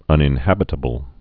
(ŭnĭn-hăbĭ-tə-bəl)